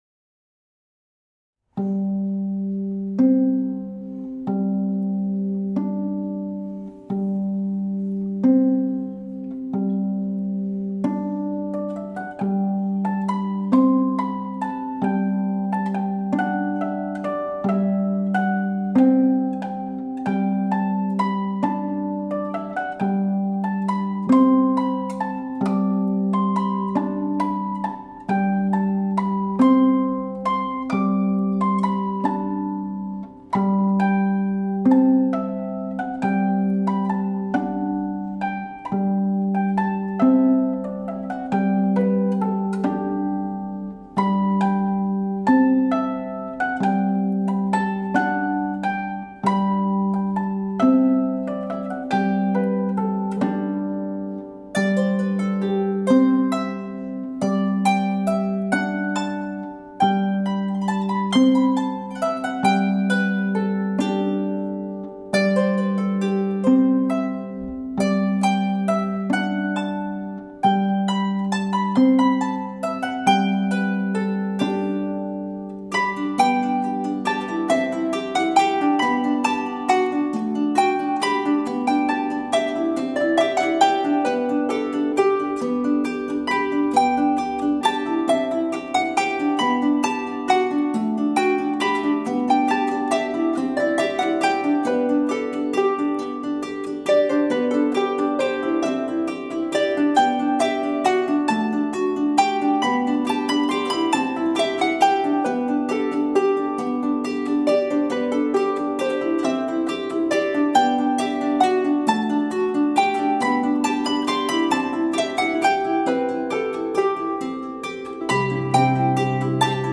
Klangbeispiel
Duett Böhmische Harfe/
Harfe nach Porträt J. Häuslers
Harfenduo Merlice, Ein Schottisch Tanz (William Brade)